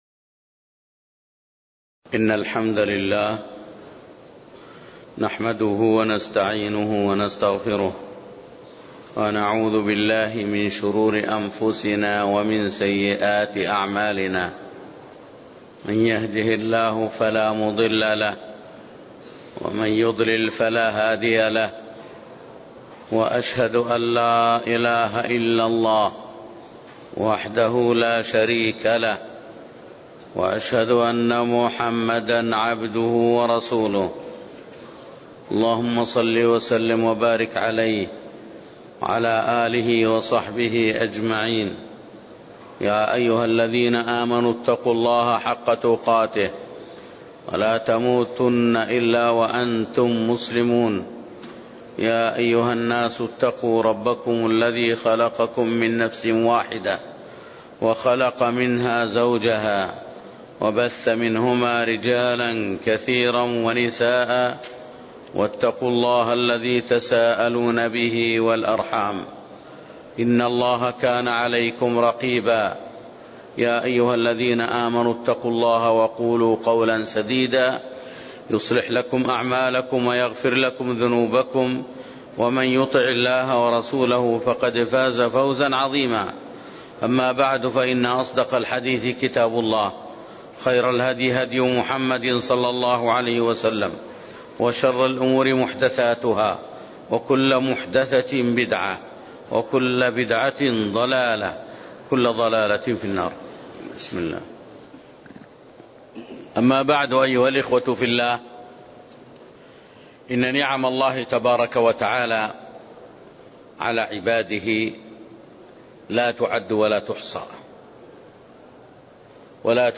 كلمة في المسجد النبوي